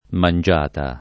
mangiata [ man J# ta ] s. f.